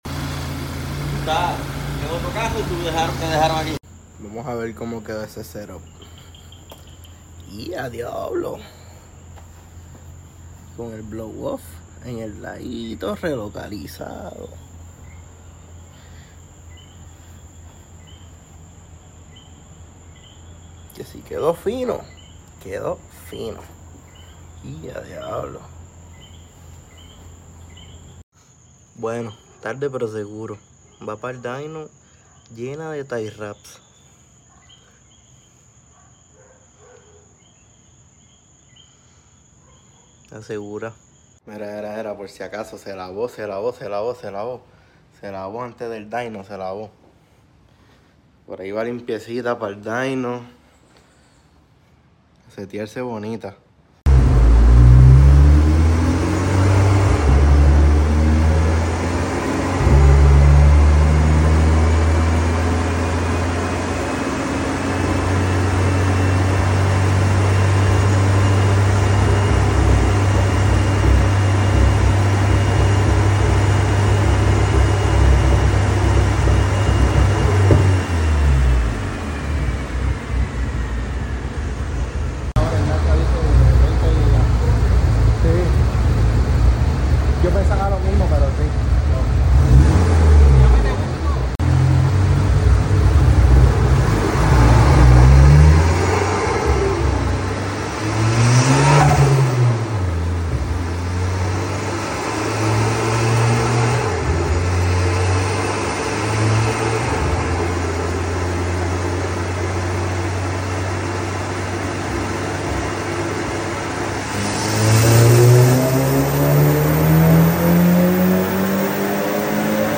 A cuppa dyno pulls lol sound effects free download